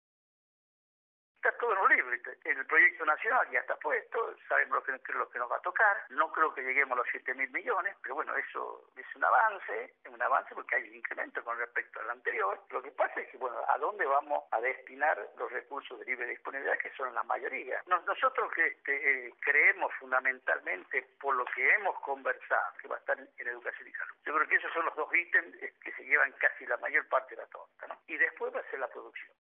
Jorge Basso, diputado provincial, por Radio La Red
jorge-basso-diputado-provincial-por-radio-la-red.mp3